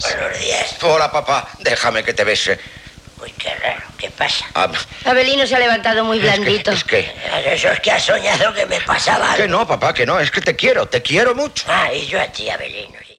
Diàleg entre Avelino i el seu pare Segismundo.
Infantil-juvenil